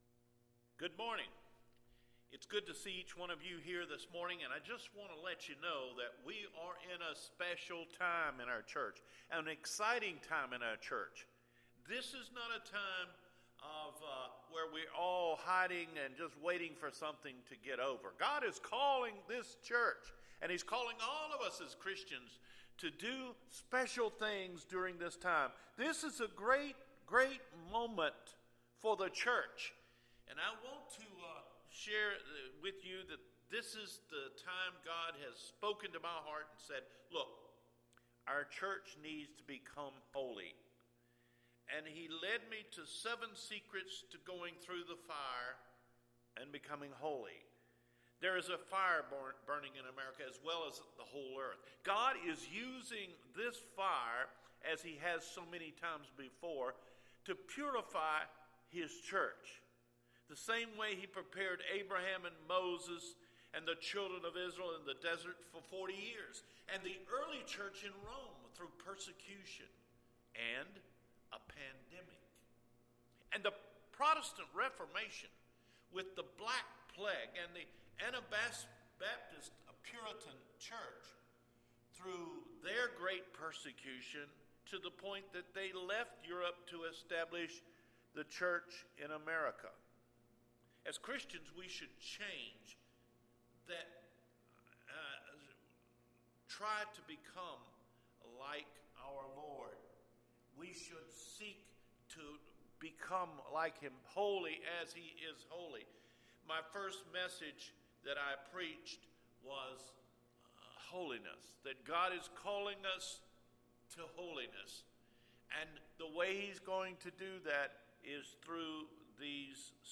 THE FOURTH & FIFTH STEPS TO HOLINESS: SACRIFICE & SERVICE – AUGUST 9 SERMON